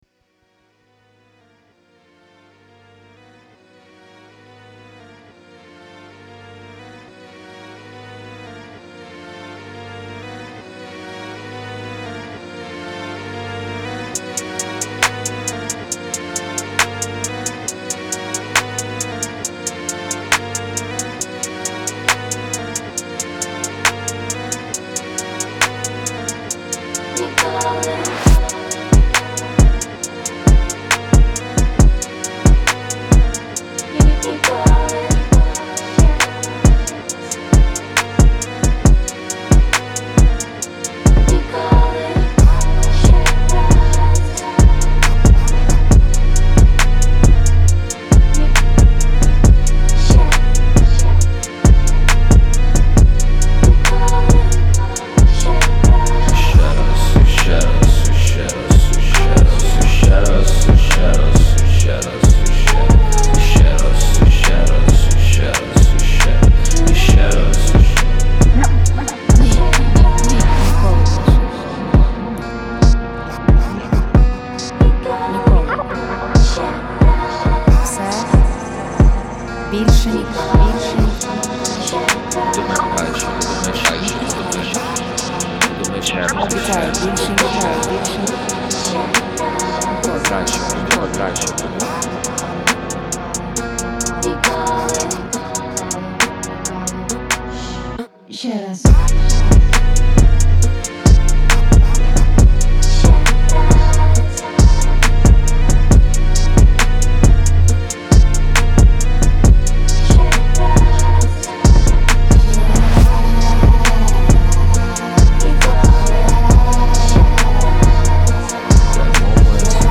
• Жанр: Soul